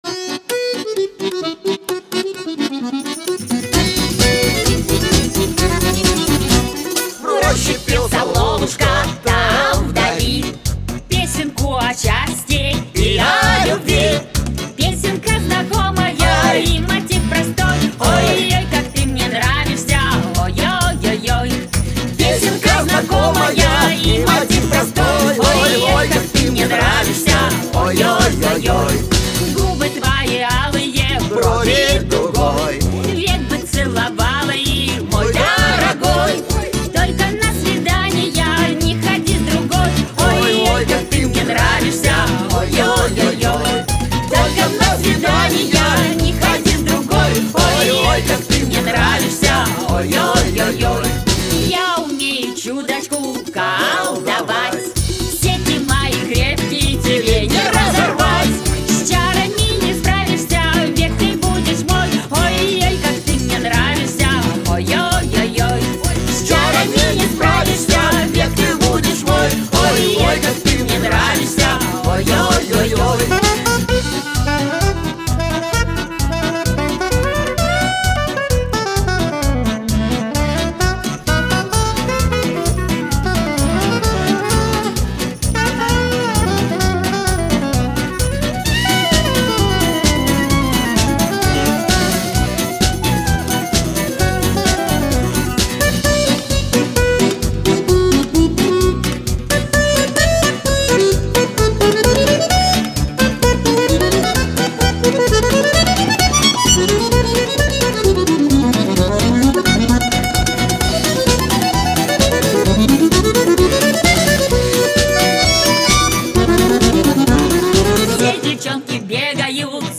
♦   Dieses neue musikprojekt ist eine fusion aus russuscher trad. musik und dem westlichen rock'n'roll, wo man die lead guitar durch ein wildes akkordeon ersetzt hat.
♦   emotional, raw, savagely passionate and virtuoso all at the same time: it's russian rock'n'roll, and we like it!.